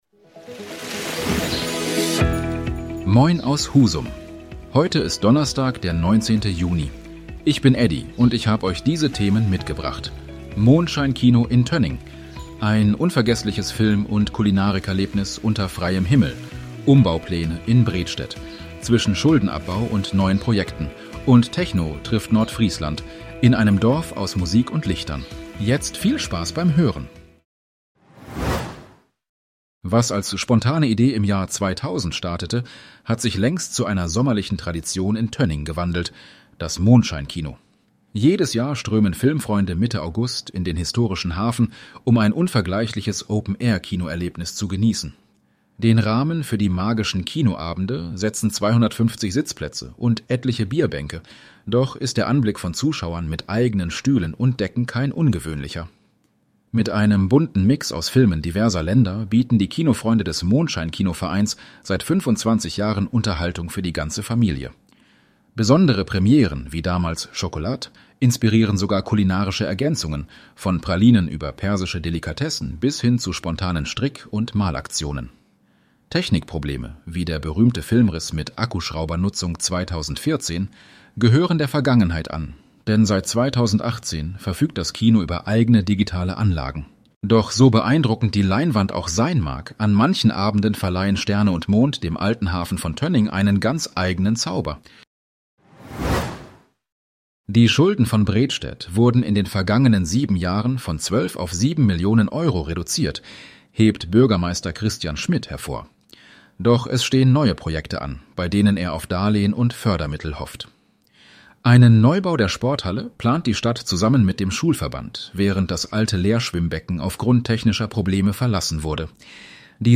Deine täglichen Nachrichten
Nachrichten